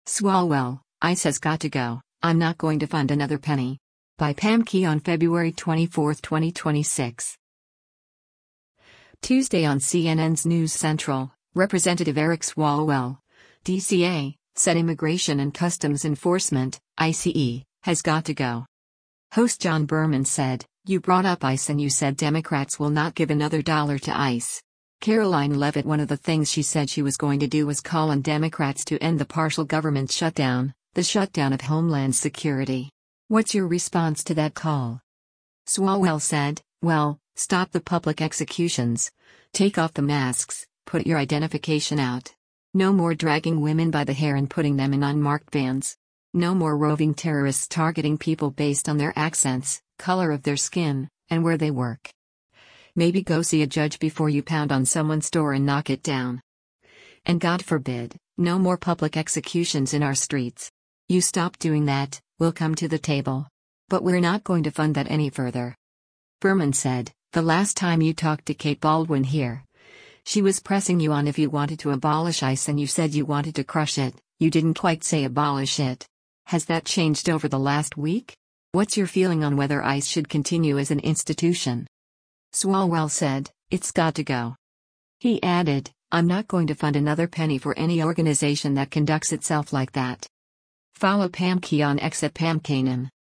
Tuesday on CNN’s “News Central,” Rep. Eric Swalwell (D-CA) said Immigration and Customs Enforcement (ICE) has “got to go.”